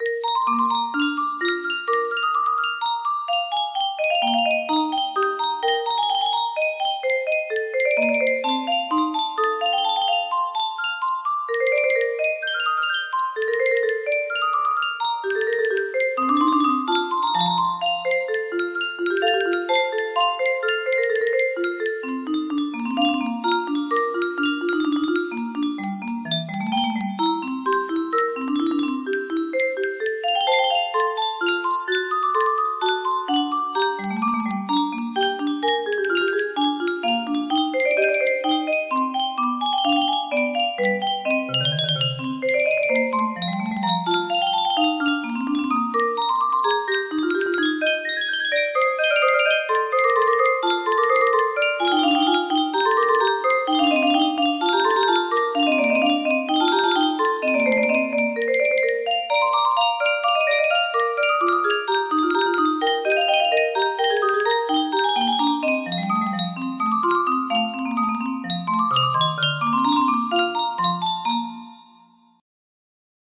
Bach Invention No. 14 in B-flat Major BWV 785 music box melody
Full range 60